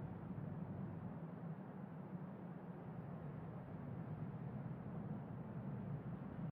白噪声房间里2.ogg